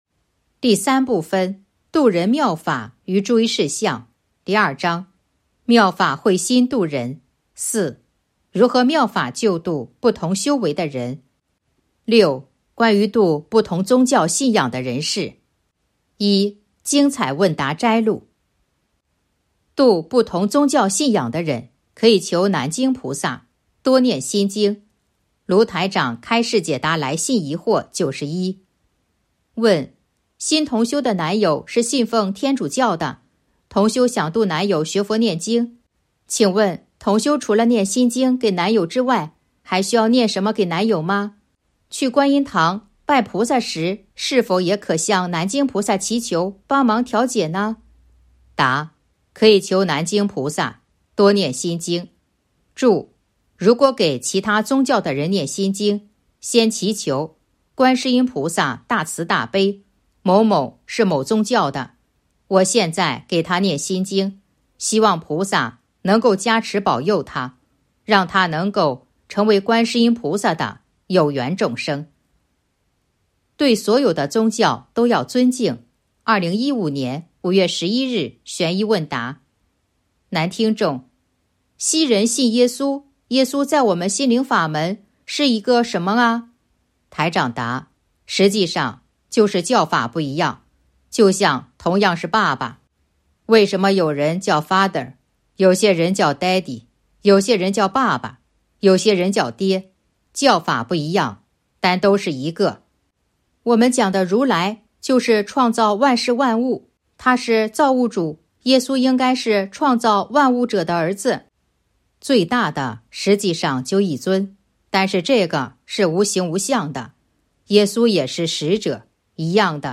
032.（六）关于度不同宗教信仰的人士 1. 精彩问答摘录《弘法度人手册》【有声书】